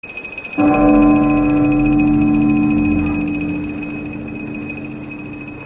• BELL TOLL AND CONTINOUS ALARM.wav
BELL_TOLL_AND_CONTINOUS_ALARM_TF6.wav